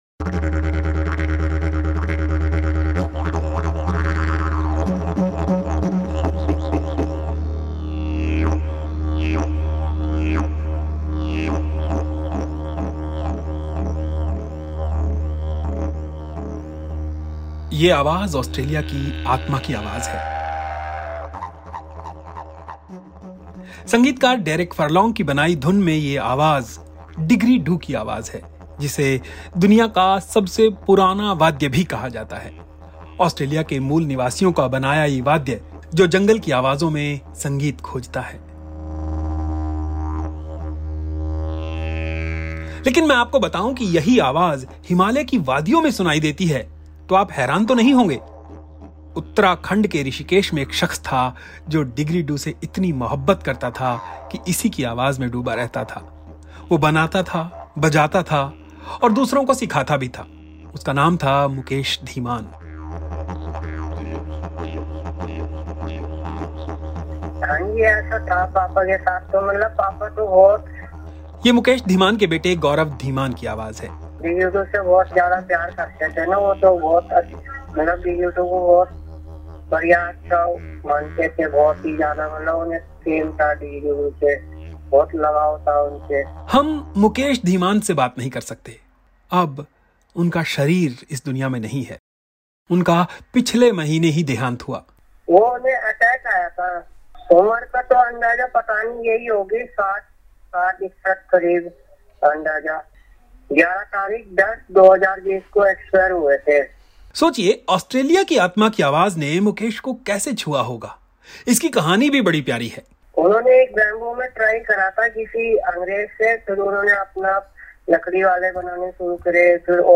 didgeridoo